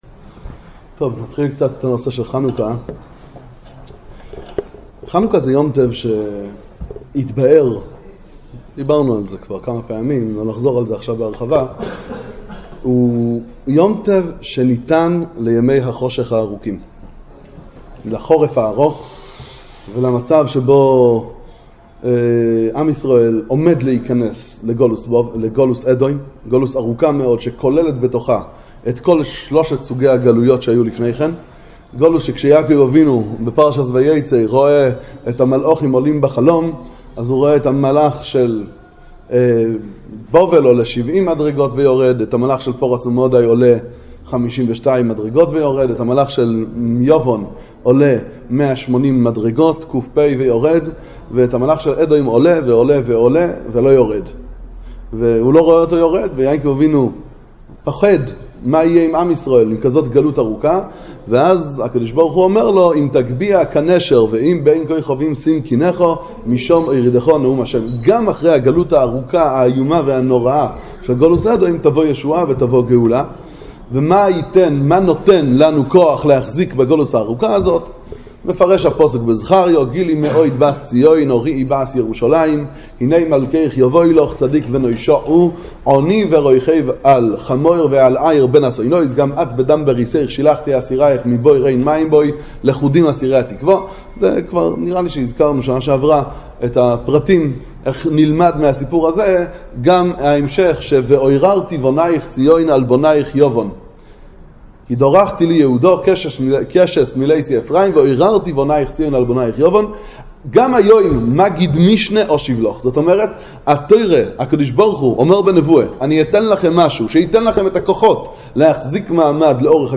למה היה צריך את נס פך השמן, הרי טומאה הותרה כשרוב הציבור טמאים? השיעור מתקיים בדרך כלל בימי שלישי בשעה 23:10 בביהמ"ד קרלין סטולין רחוב בן יעקב 23 בני ברק, ומיועד לאברכים ובחורי ישיבה.